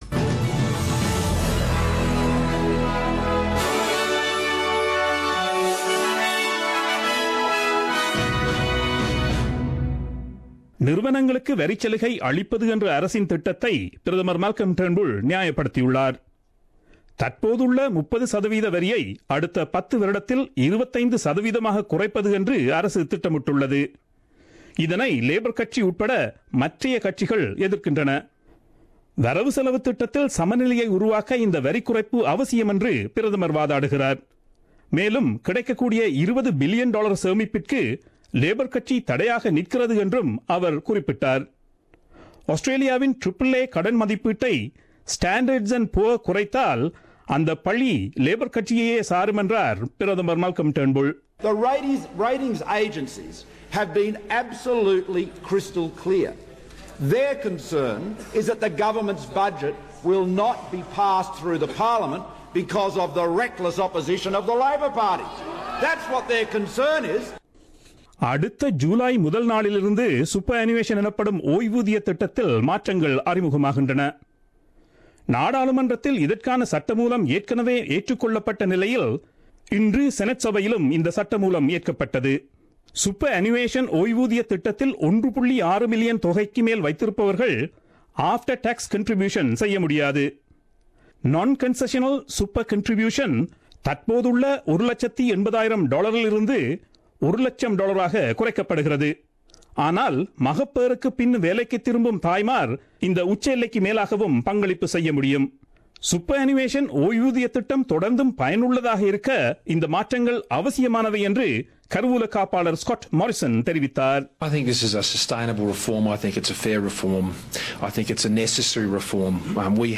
The news bulletin aired on 23 November 2016 at 8pm.